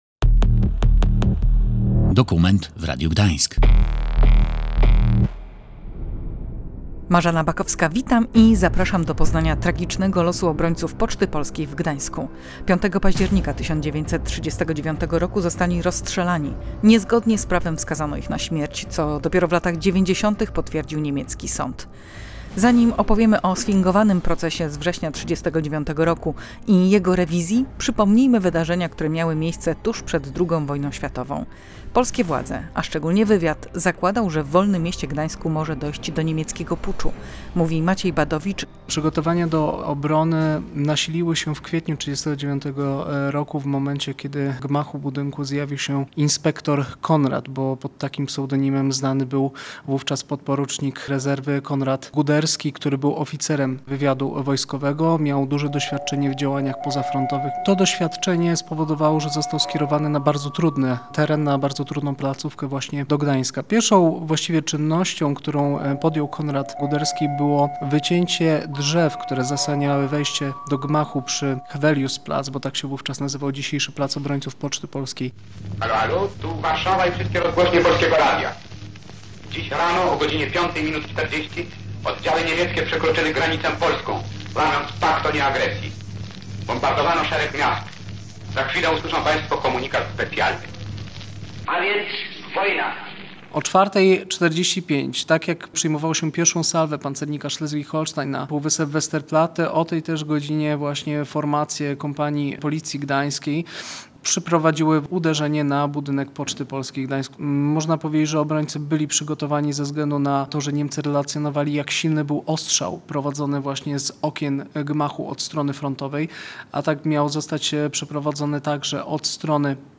Audycja dokumentalna opowiada o niezwykłej obronie Polaków oraz o tym, jak doszło do mordu sądowego. Sięga lat 90. XX wieku, kiedy to przypadkowo na gdańskiej Zaspie odkryto grób pocztowców. Przybliża także rehabilitację bohaterów przed niemieckim sądem.